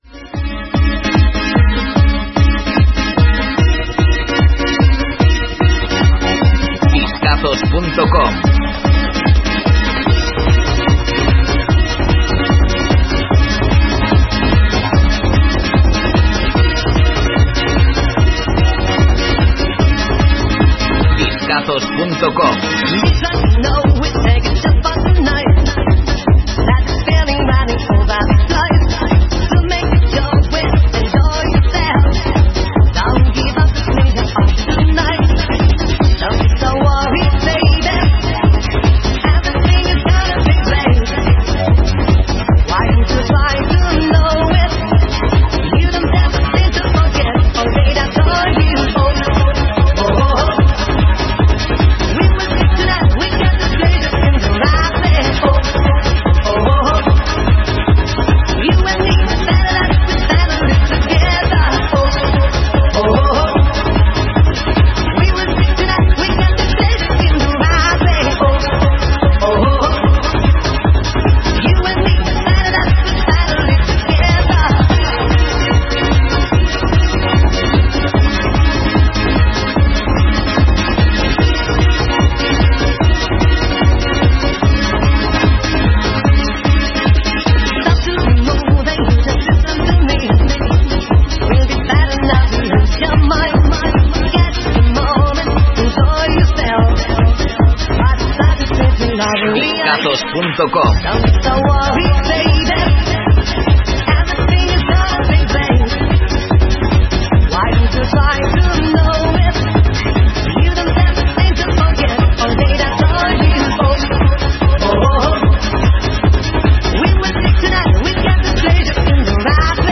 Trance Club Mix